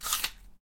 吃苹果脆片 " 苹果脆片09
描述：09/18苹果相关的吃东西的声音。 在我的工作室里用一对Rode NT5的XY配置进行录音。 立体声，WAV，44.1KHz，16Bit，未处理。
标签： 苹果 紧缩 饮食 食品 小吃 静噪